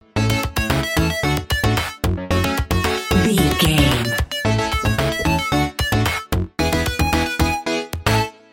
Ionian/Major
bouncy
bright
cheerful/happy
funky
lively
playful
uplifting
synthesiser
drum machine